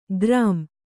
♪ grām